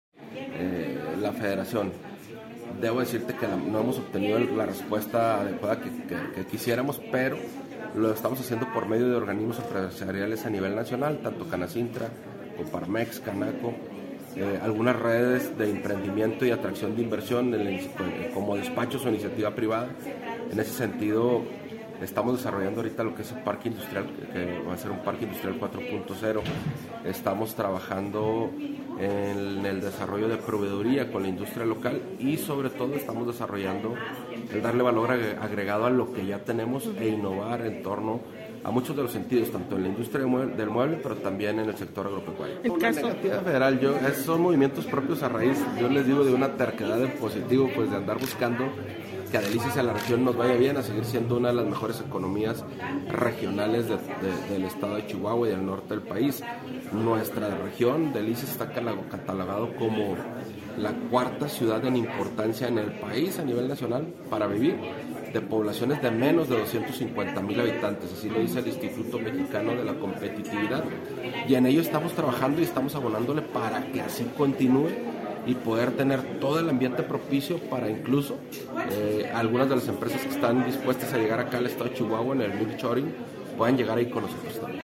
AUDIO: JESÚS VALENCIANO, PRESIDENTE MUNICIPAL DE DELICIAS